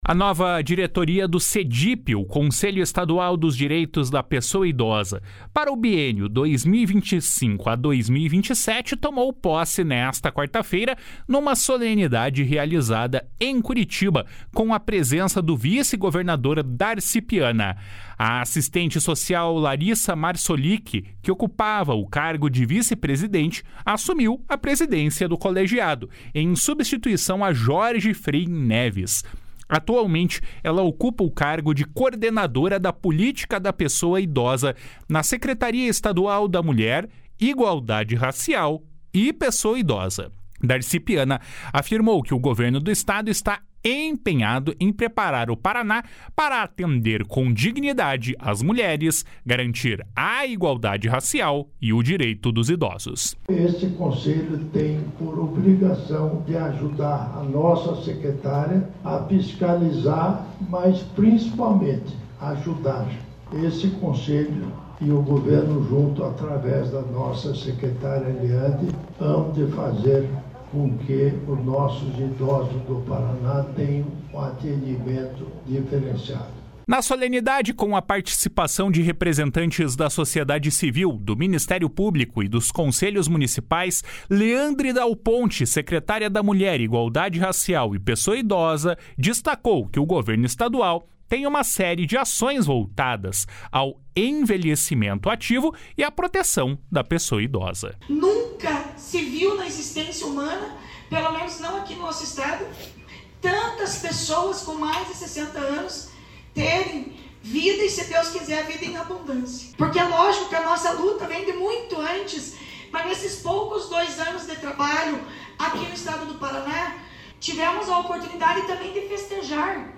// SONORA DARCI PIANA //